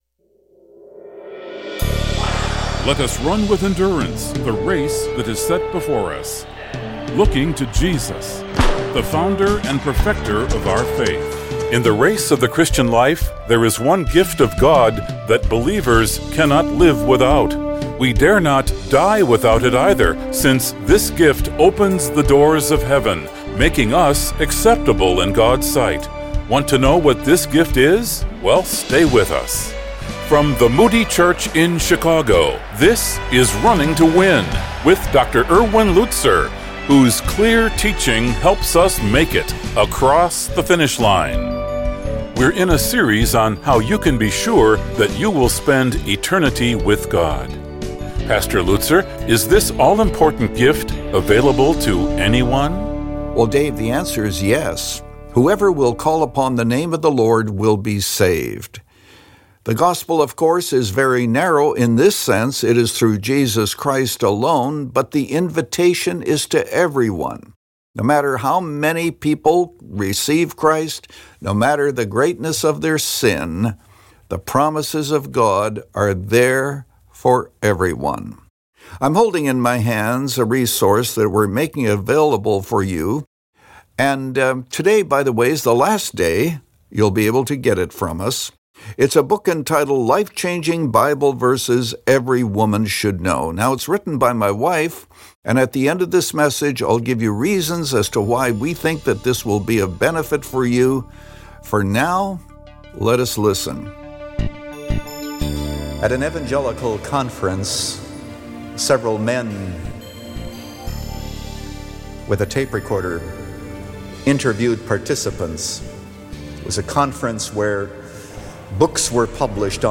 In this message from 2 Corinthians 5